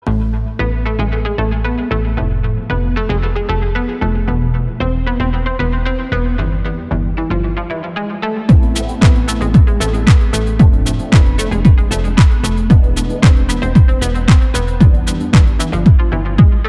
• Качество: 256, Stereo
ритмичные
deep house
спокойные
без слов